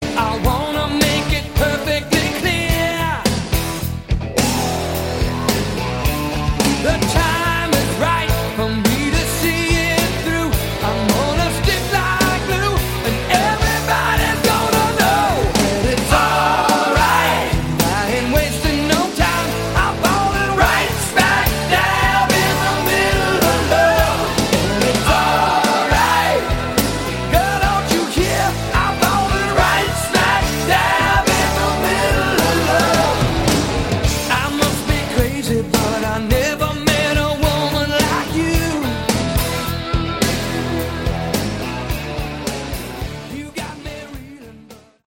Category: West Coast
Guitars
Vocals, Bass
Vocals, Drums
Keyboards
Hammond B3
Backing Vocals